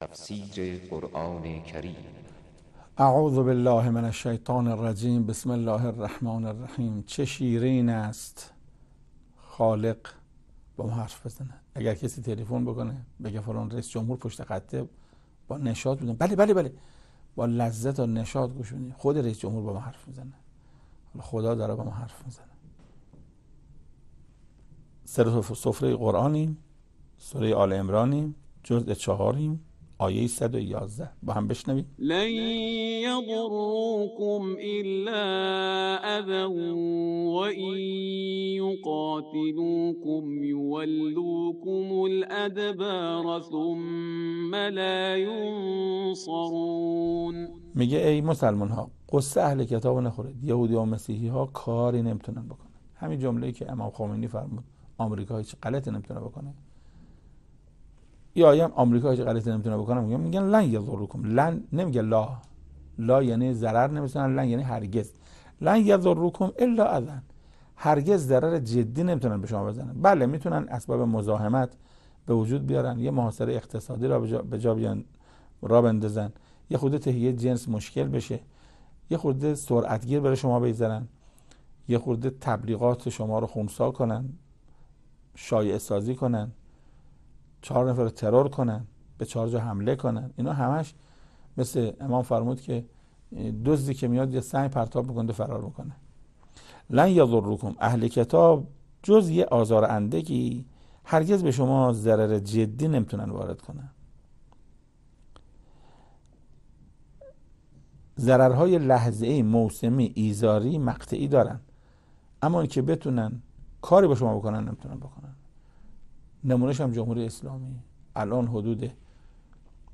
تفسیر آیه 111 سوره آل عمران - استاد محسن قرائتی | ضیاءالصالحین
تفسیر آیه 111 سوره آل عمران - استاد محسن قرائتی در این بخش از ضیاءالصالحین، صوت تفسیر آیه صد و یازدهم سوره مبارکه آل عمران را در کلام حجت الاسلام استاد محسن قرائتی به مدت 8 دقیقه با شما قرآن دوستان عزیز به اشتراک می گذاریم.